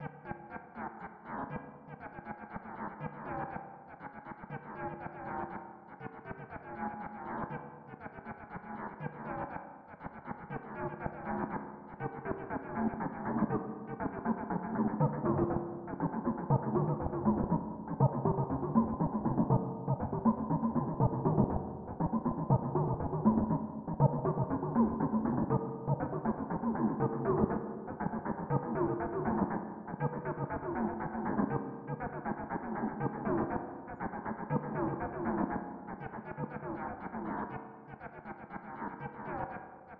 鼓打击乐器合成节拍循环
描述：康加鼓和怪异的合成声音。 用免费的E MU Proteus vsti进行排序。 90 bpm。 录制在Cubase中。 查看我的其他内容。
标签： 合成音 奇怪 欢乐 喝醉的 节奏 玩笑 滑稽 韵律 非洲 打击乐器 节拍 快活 康加 卡通片 循环 groove
声道立体声